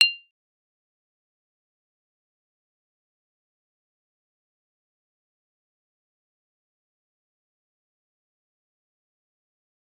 G_Kalimba-F7-f.wav